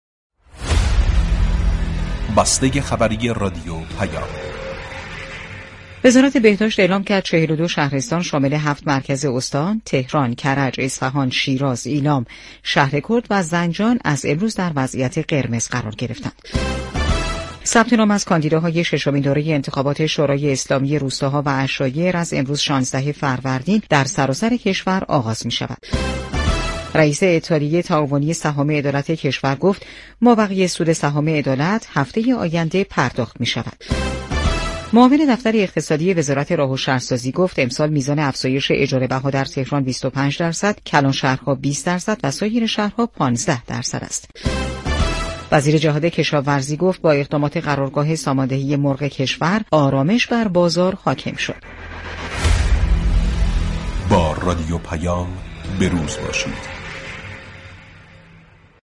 بسته خبری رادیو پیام